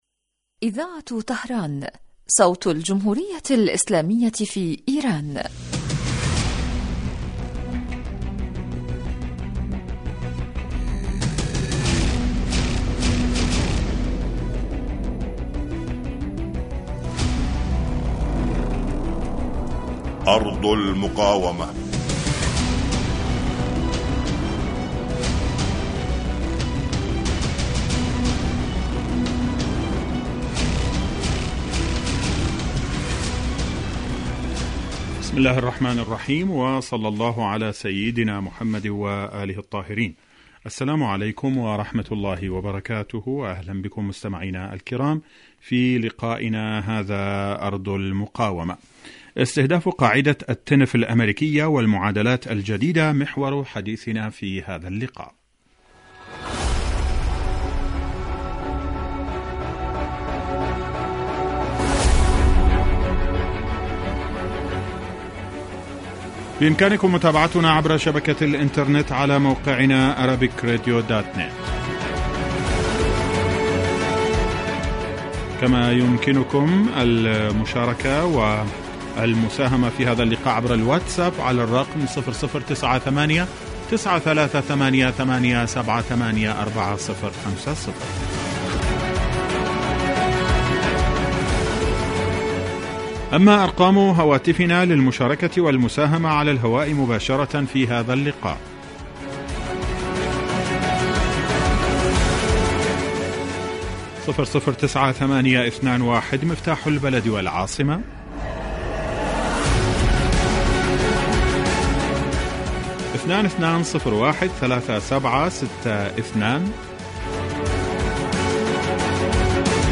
برنامج إذاعي حي لنصف ساعة يتناول بالدراسة والتحليل آخر التطورات والمستجدات على صعيد سوريا والأردن وفلسطين المحتلة ولبنان.
يستهل المقدم البرنامج بمقدمة يعرض فيها أهم ملف الأسبوع ثم يوجه تساؤلاته إلى الخبراء السياسيين الملمين بشؤون وقضايا تلك الدول والذين تتم استضافتهم عبر الهاتف .